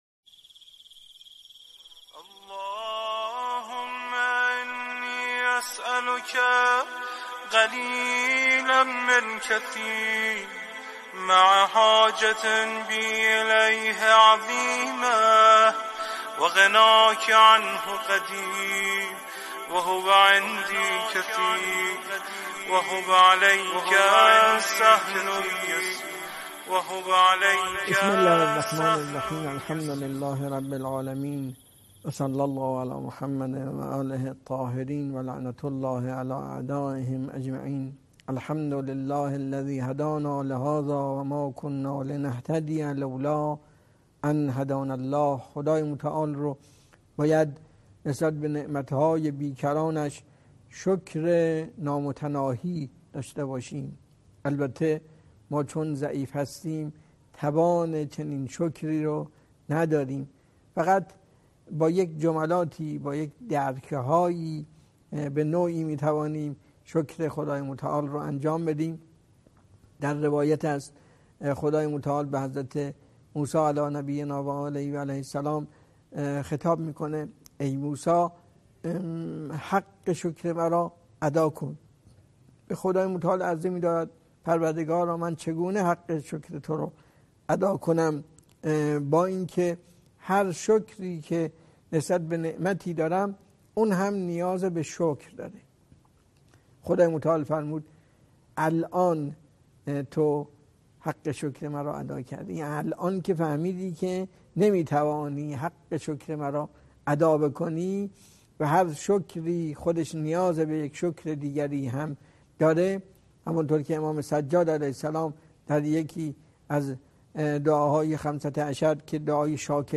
سخنرانی ماه رمضان